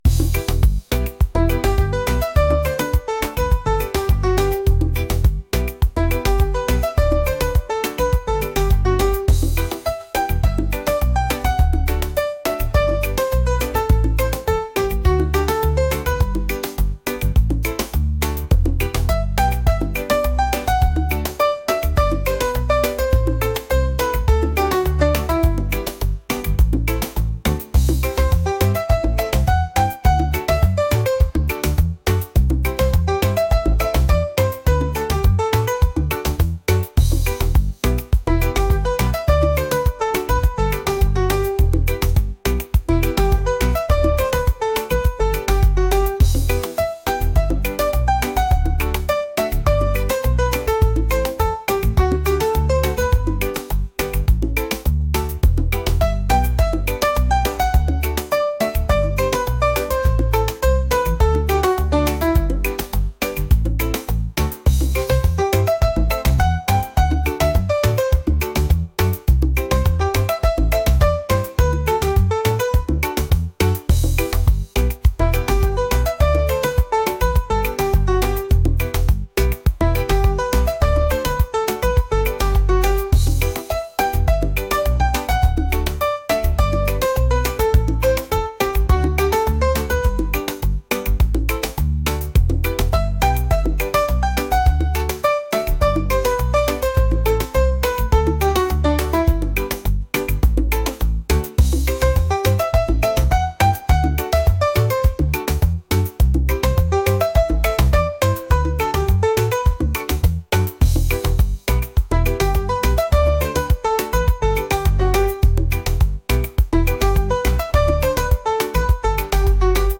reggae | funk | soul & rnb